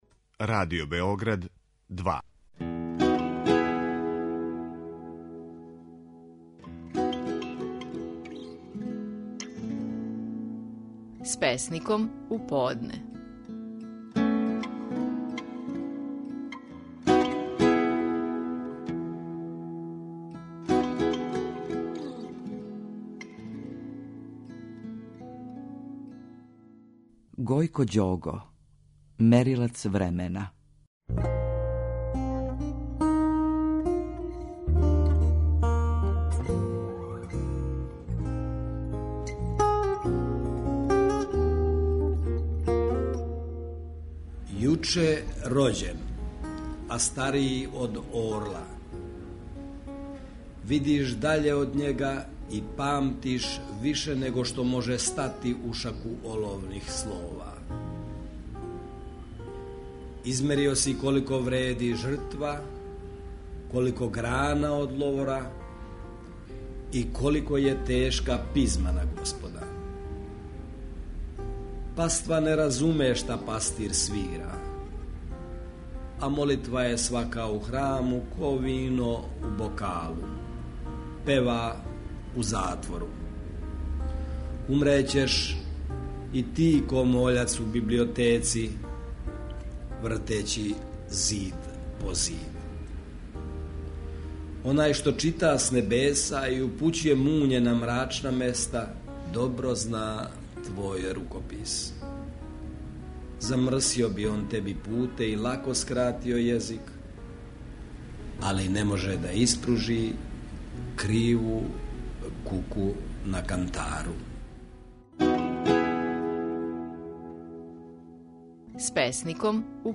Наши најпознатији песници говоре своје стихове.
У данашњој емисији, песму "Мерилац времена" говори Гојко Ђого.